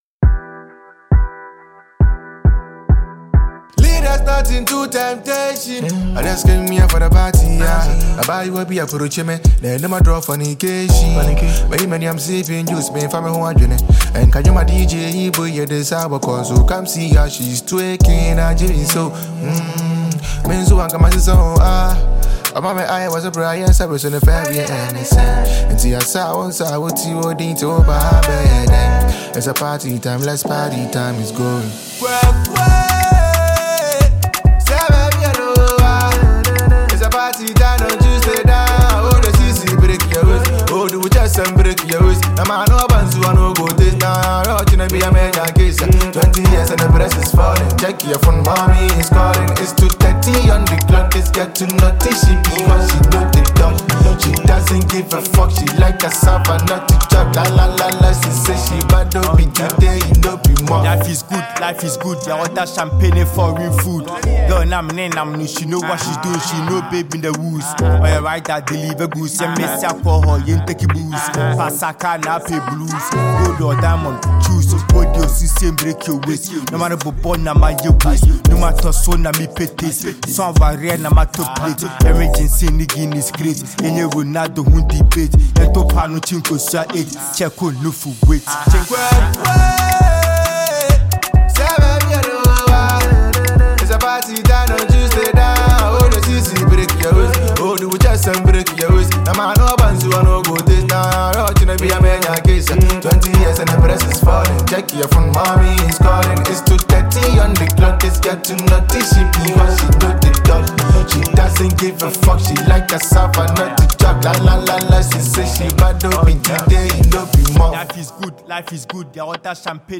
Asakaa rapper and songwriter